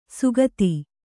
♪ sugati